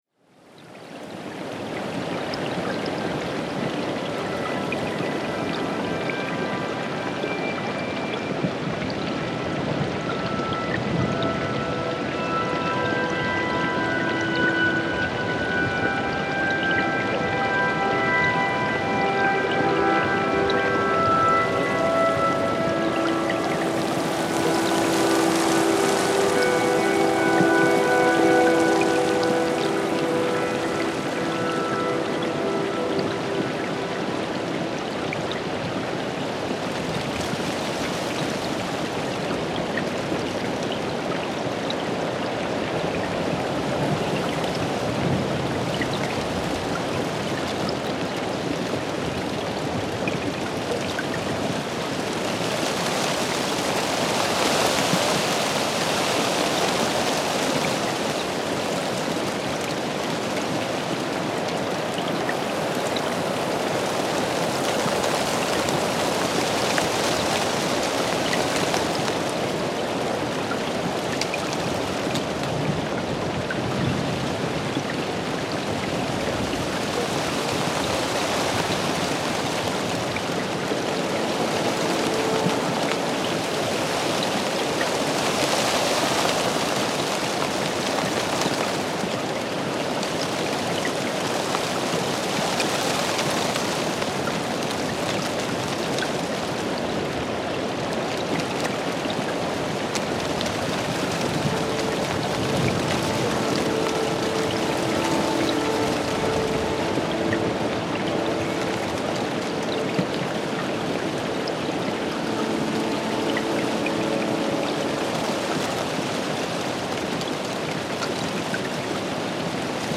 Klikkaa tästä äänimatto lukukokemuksesi taustalle: